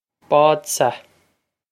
Bádsa Bawd-sah
This is an approximate phonetic pronunciation of the phrase.